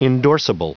Prononciation du mot endorsable en anglais (fichier audio)
Prononciation du mot : endorsable